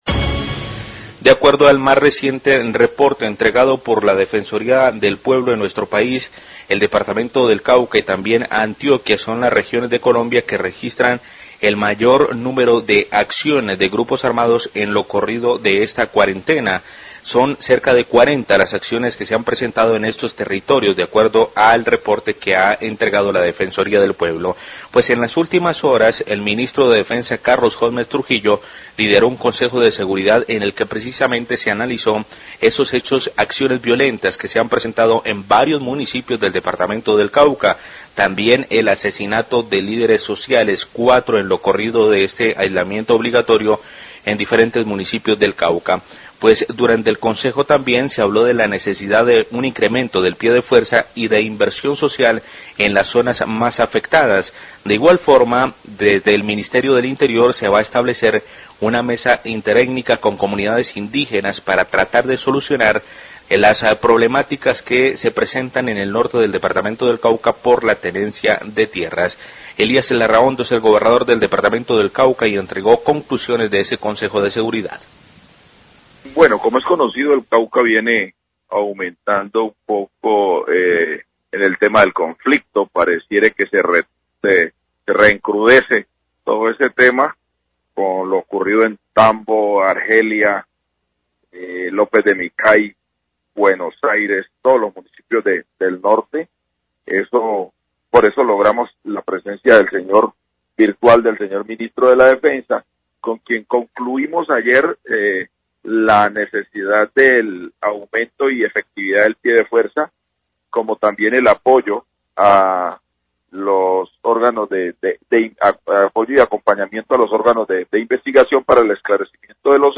Gobernador Cauca habla de consejo de seguridad por orden público y problemática tierras,
El Gobernador del Cauca, Elias Larrahondo, habla de las conclusiones luego del consejo de seguridad liderado por el Ministro de Defensa donde se estableció una ruta de trabajo entre el Ministerio dle Interior, las comunidades indígenas y los propietarios de cultivos caña de azúcar.